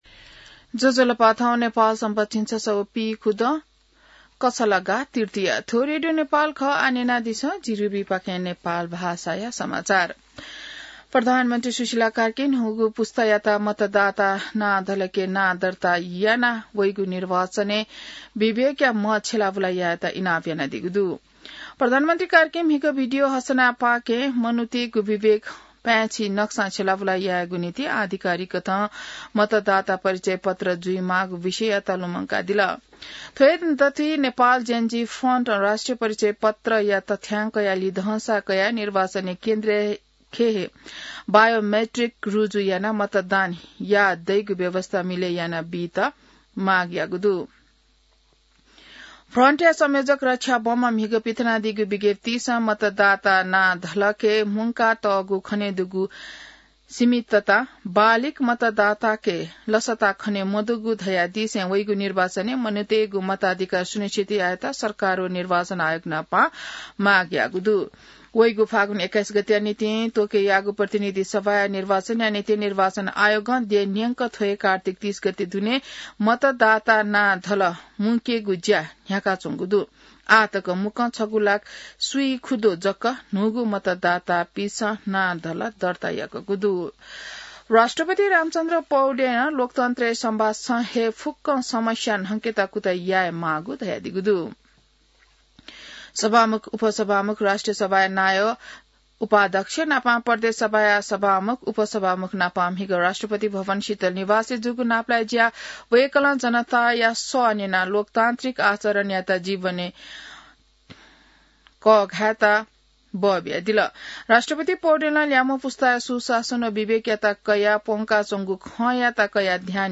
नेपाल भाषामा समाचार : २२ कार्तिक , २०८२